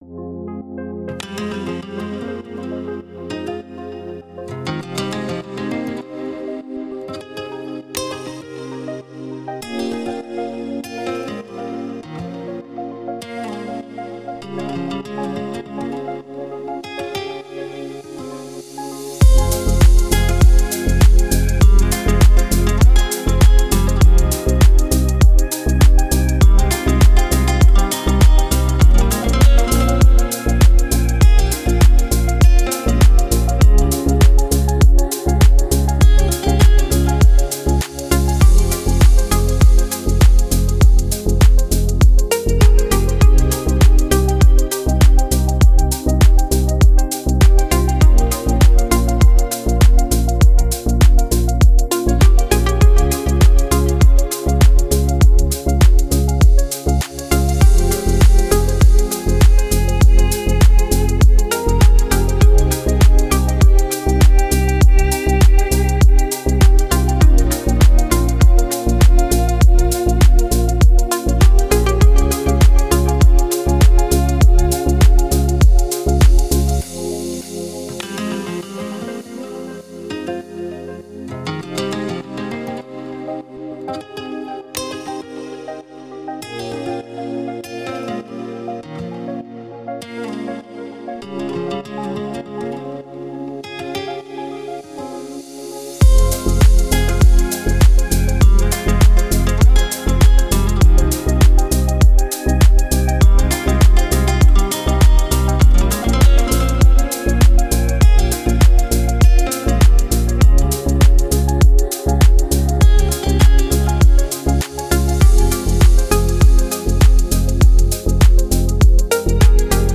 Deep House музыка
дип хаус